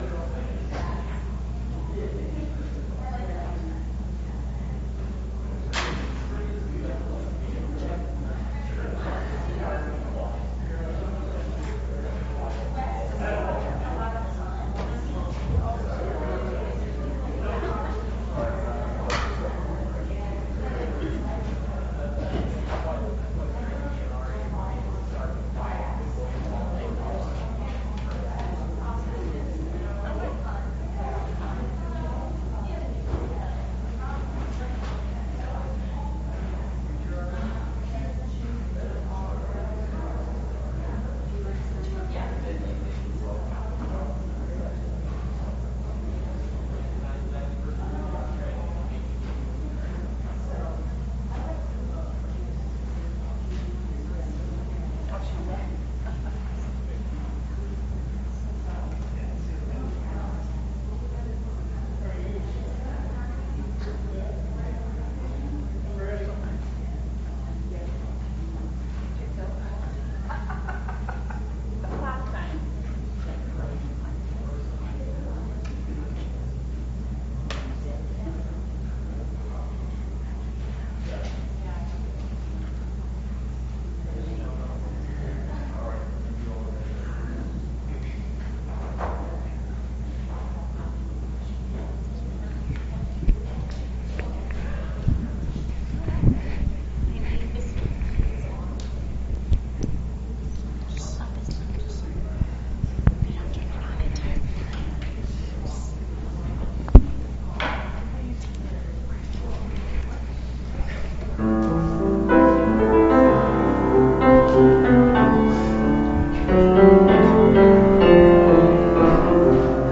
Please, click the arrow below to hear March 19, 2017's service.